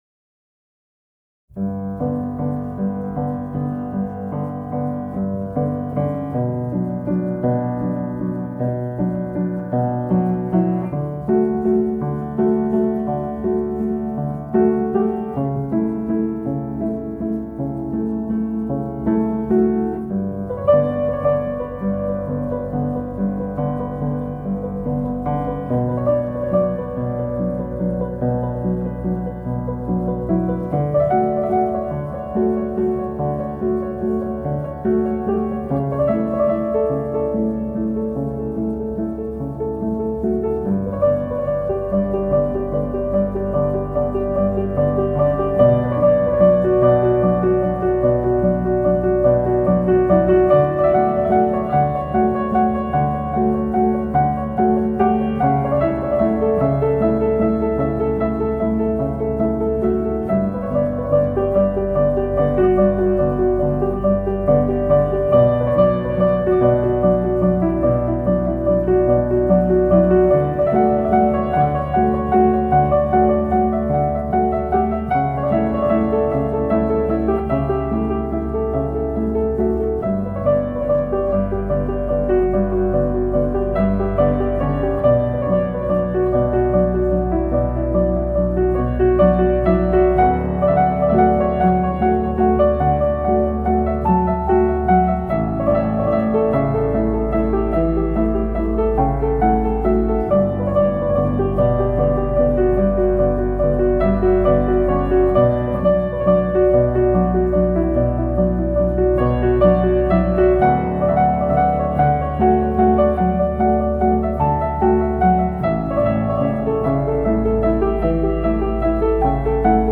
پیانو مدرن کلاسیک موسیقی بی کلام
موسیقی بی کلام احساسی موسیقی بی کلام عمیق